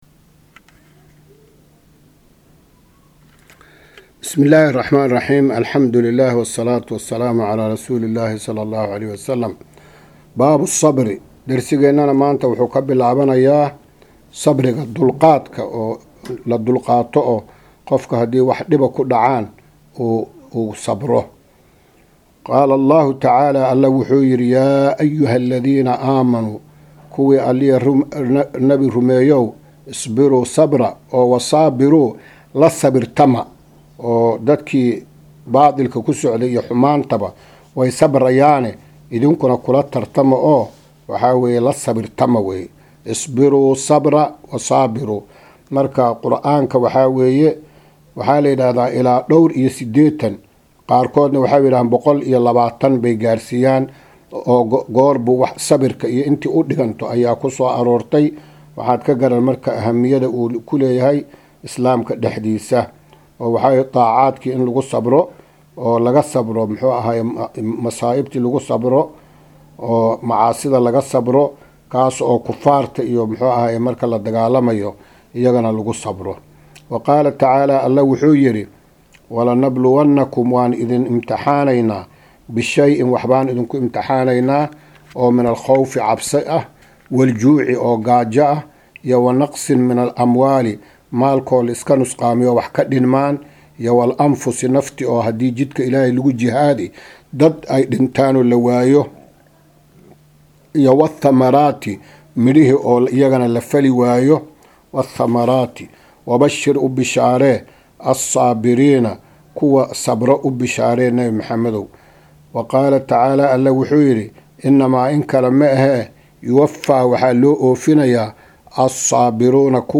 Maqal- Riyaadu Saalixiin – Casharka 5aad